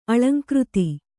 ♪ aḷaŋkřti